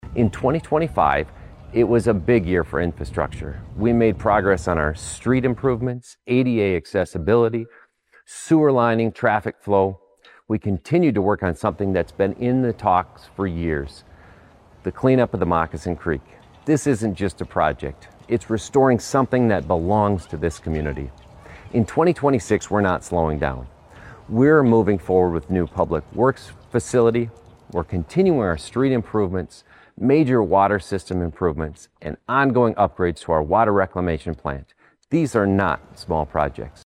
The address was delivered at Storybook Land.